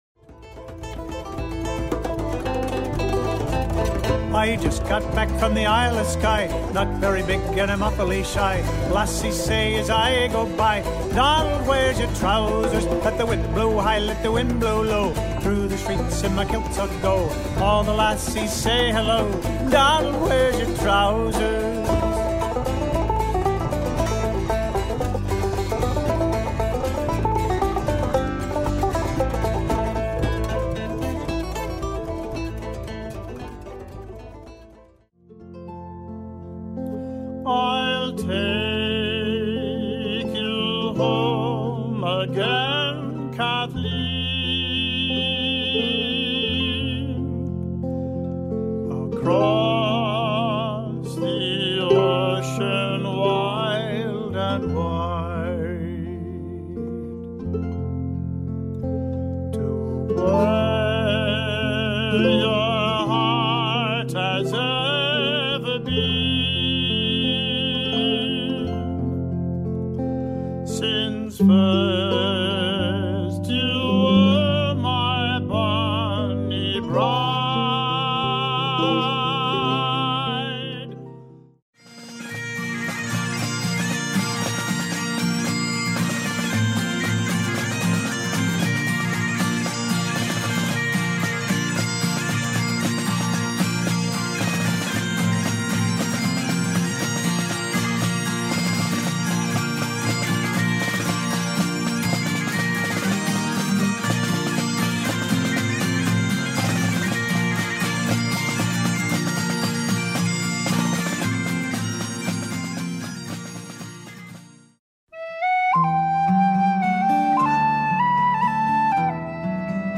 Instruments include: tenor banjo, mandolin, guitar, bagpipes, bass, bodhran, harmonica and pennywhistle.
Irish Band
Irish_medley_long1.mp3